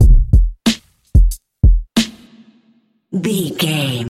Aeolian/Minor
drum machine
synthesiser
electric piano
hip hop
soul
Funk
acid jazz
energetic
bouncy
funky